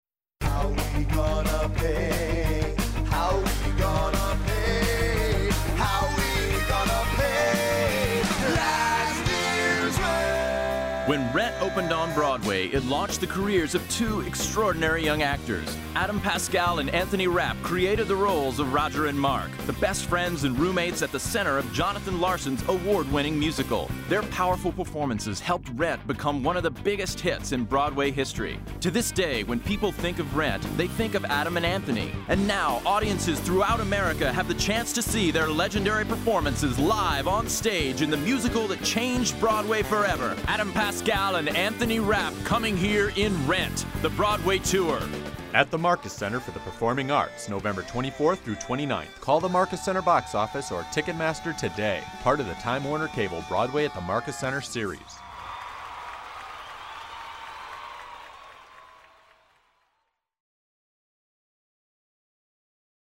RENT Radio Commercial